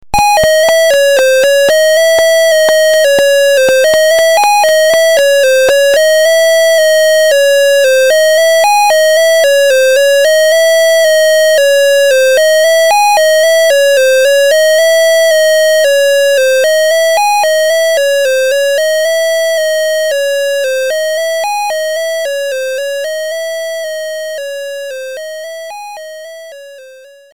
flute